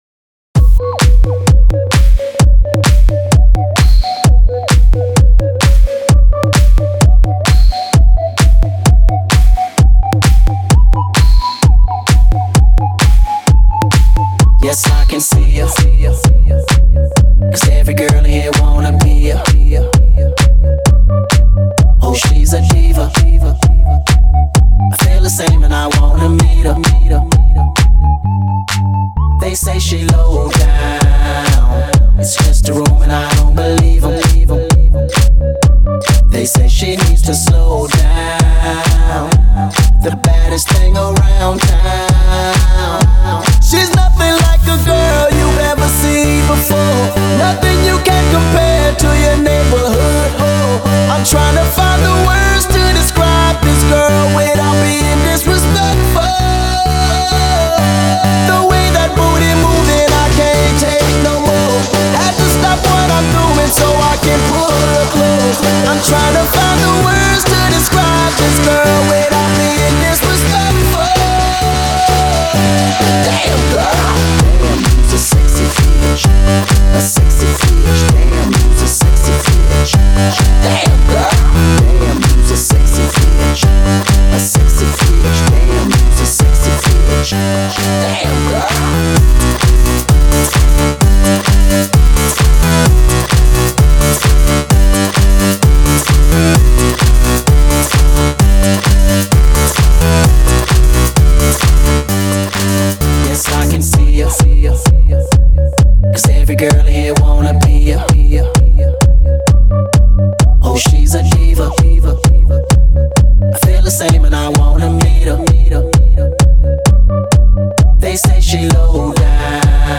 Категория: Electro House